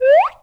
pgs/Assets/Audio/Comedy_Cartoon/whistle_slide_up_03.wav
whistle_slide_up_03.wav